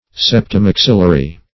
Search Result for " septomaxillary" : The Collaborative International Dictionary of English v.0.48: Septomaxillary \Sep`to*max"il*la*ry\, a. (Anat.)